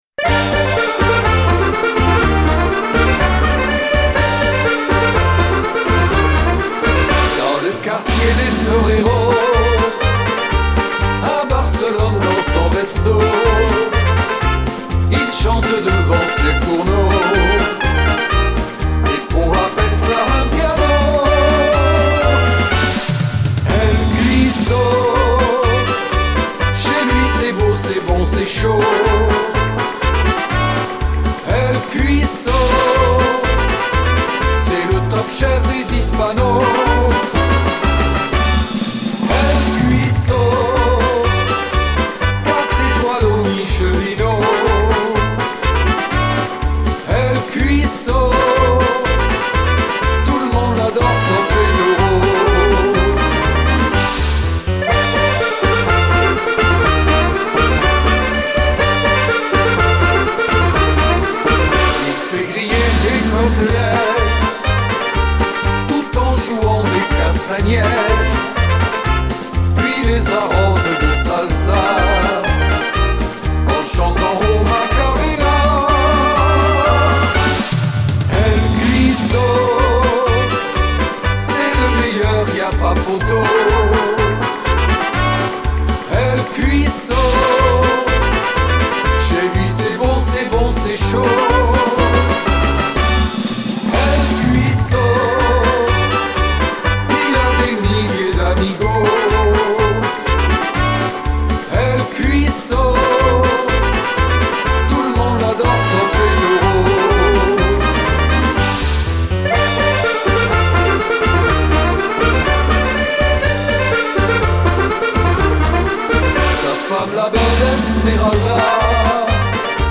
Paso humoristique
version chantée